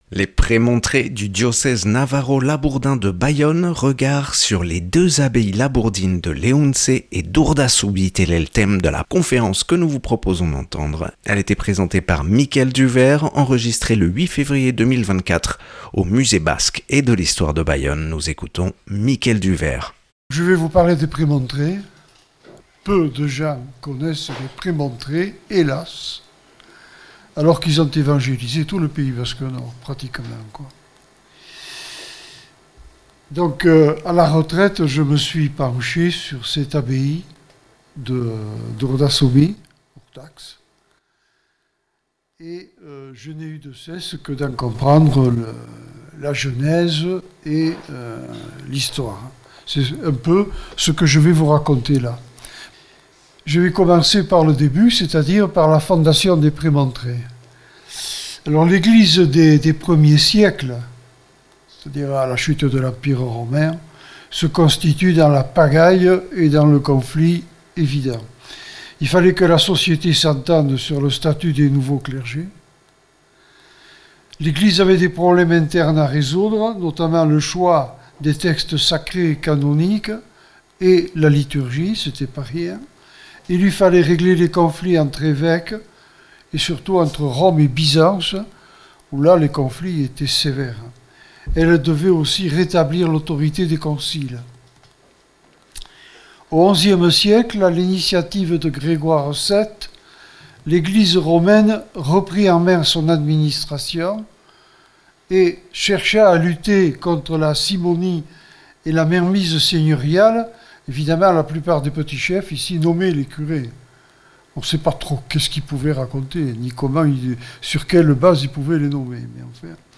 Conférence
(Enregistré le 08/02/2024 au Musée Basque et de l’histoire de Bayonne).